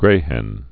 (grāhĕn)